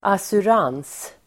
Uttal: [asur'an:s (el. -'ang:s)]